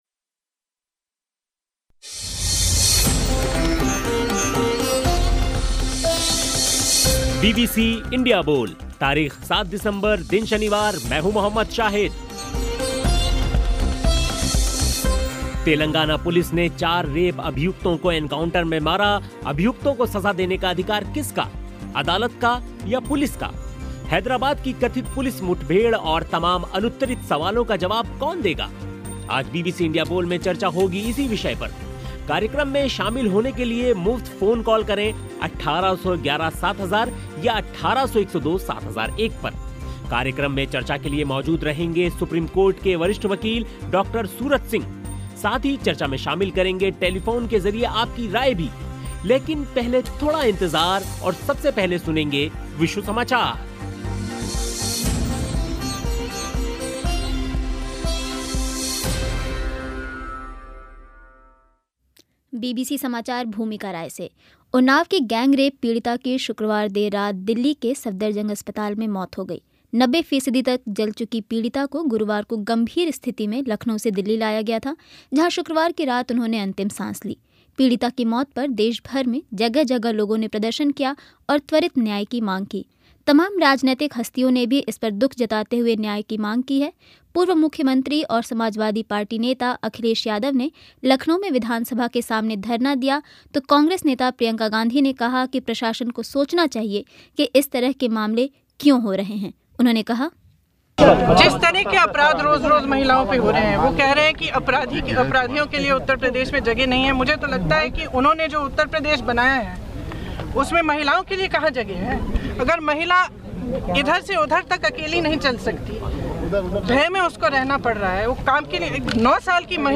Headliner Embed Embed code See more options Share Facebook X तेलंगाना पुलिस ने चार रेप अभियुक्तों को एनकाउंटर में मारा अभियुक्तों को सज़ा देने का अधिकार किसका, अदालत का या पुलिस का हैदराबाद की कथित पुलिस मुठभेड़ और तमाम अनुत्तरित सवालों का जवाब कौन देगा? आज बीबीसी इंडिया बोल में चर्चा हुई इसी विषय पर.
लेकिन सबसे पहले सुनिए विश्व समाचार.